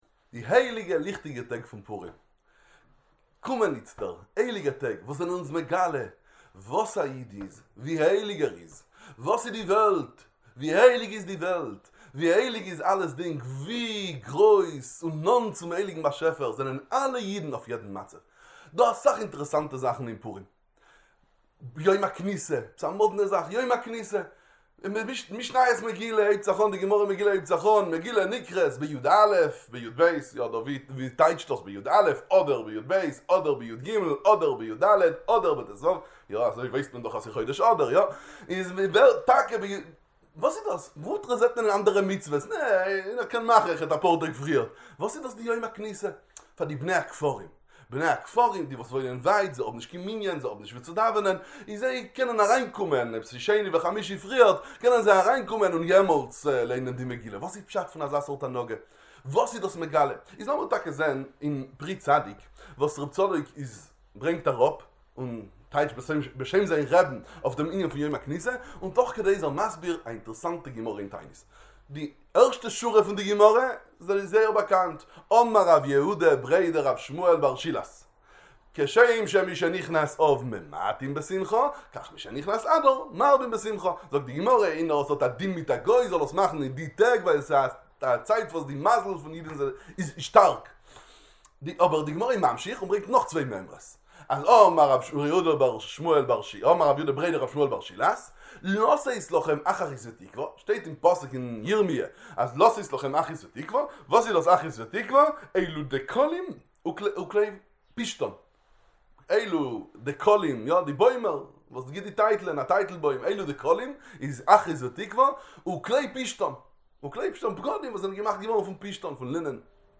שיעור מדברי רבי צדוק הכהן מלובלין